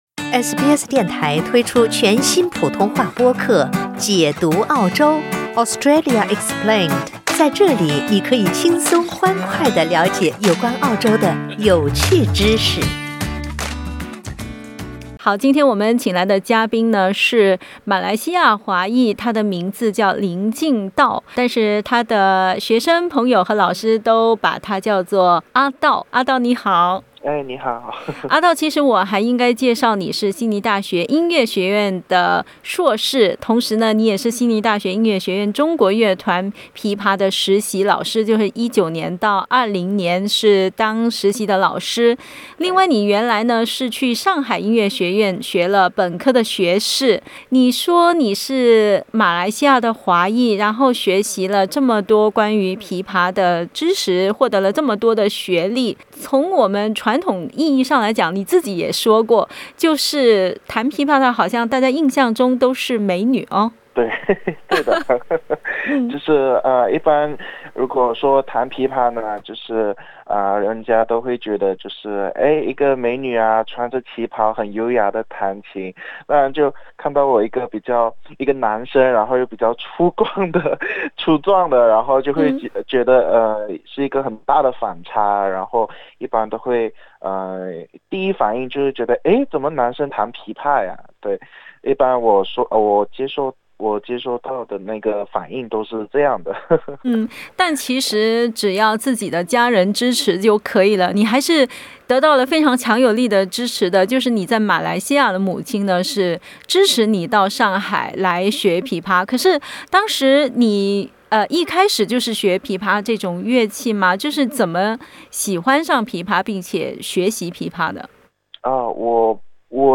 Source: Supplied （请听采访） 澳大利亚人必须与他人保持至少1.5米的社交距离，请查看您所在州或领地的最新社交限制措施。